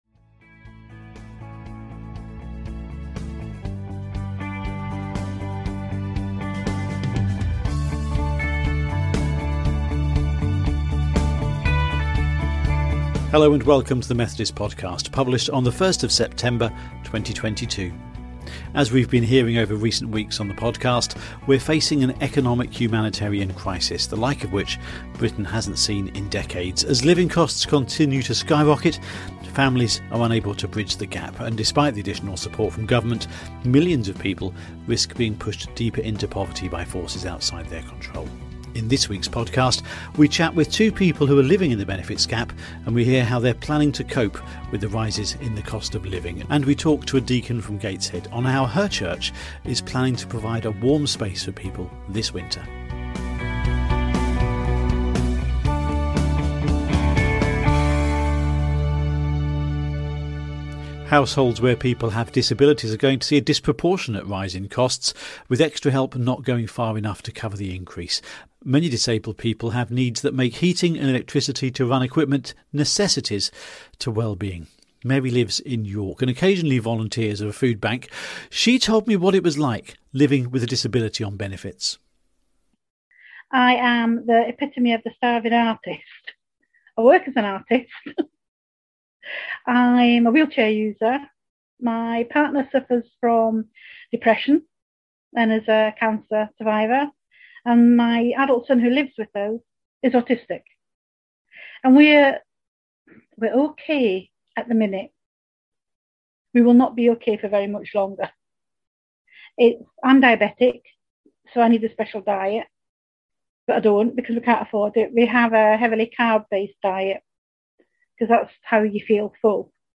In this week’s podcast, we chat with two people who are living in the benefits gap and hear how they are planning on coping with the cost of living rises. We talk to a Deacon from Gateshead on how her church will be providing a warm space for people.